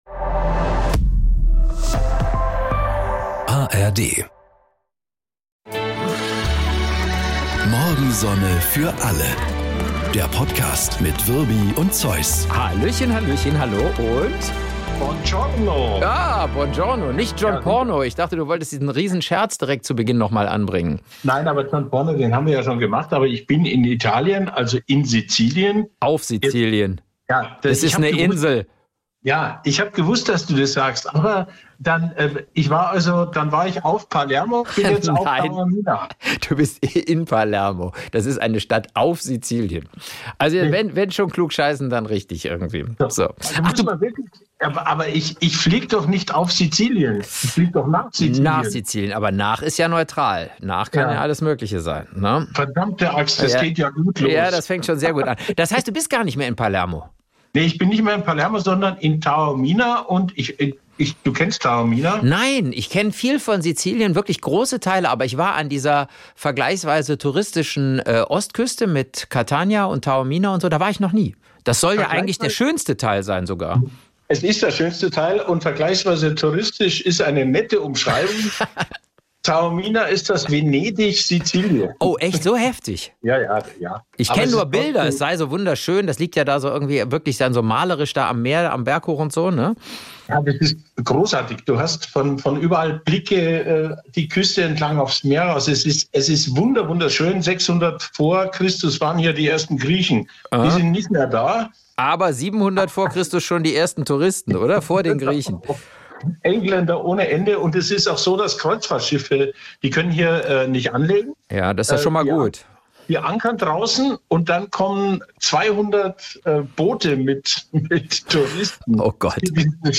Und sind damit noch munterer für diesen Podcast: Ein großer, dicker Bayer und ein kleiner, schmaler Rheinländer machen große, dicke Gags und kleine, schmale Gemeinheiten.
Komödie